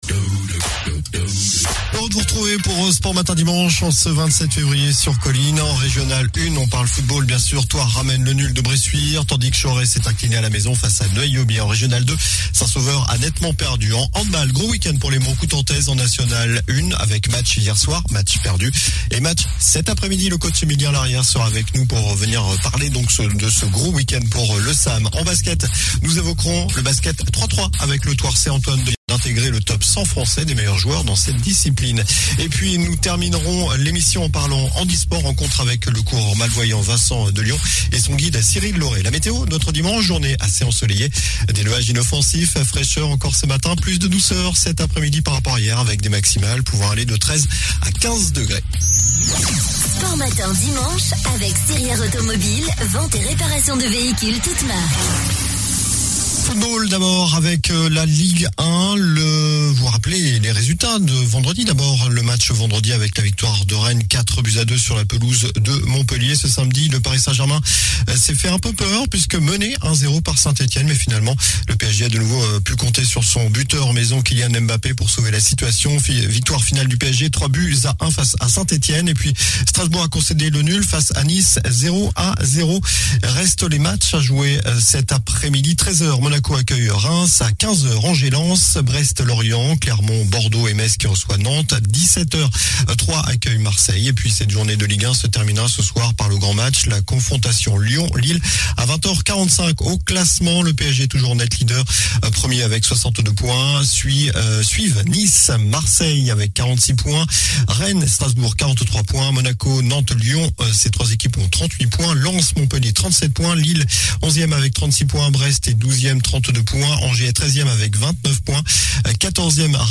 sport infos